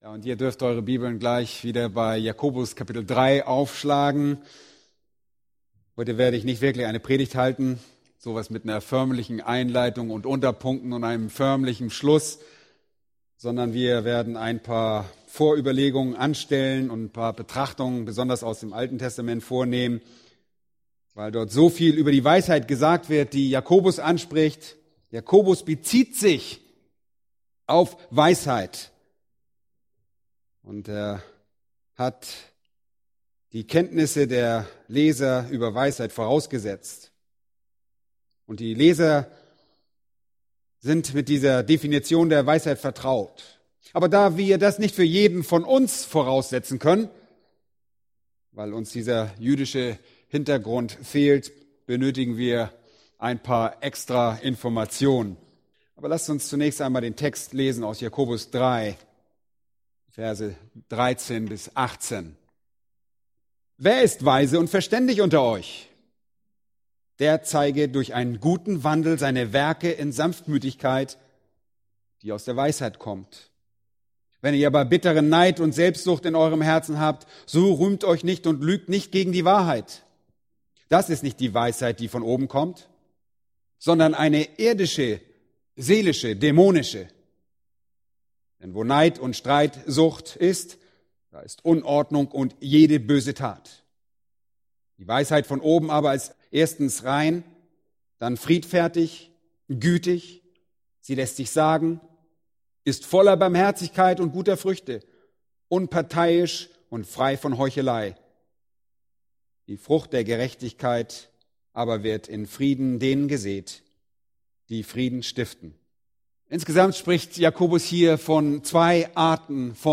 Eine predigt aus der serie "Weisheit Praktisch*." Epheser 5,15